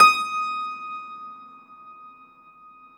53a-pno19-D4.wav